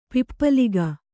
♪ pippaliga